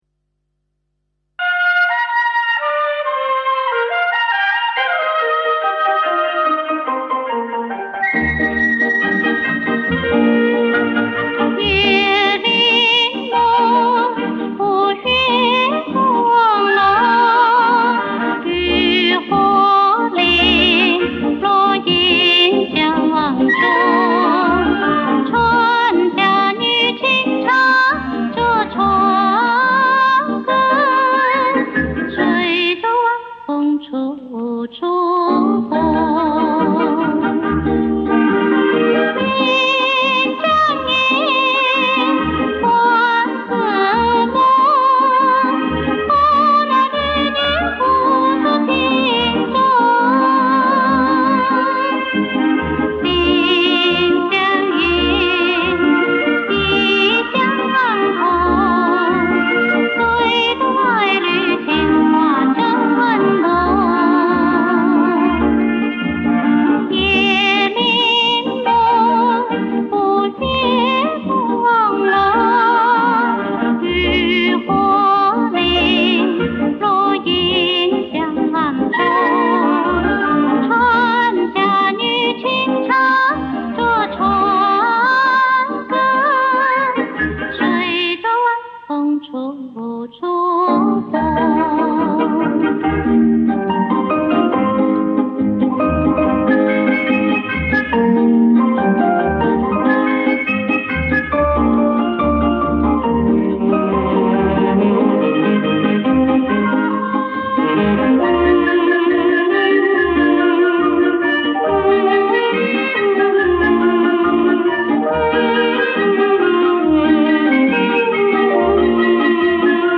原版黑膠唱片的封面
爽朗中帶有鼻音，擅長抒情幽怨的歌曲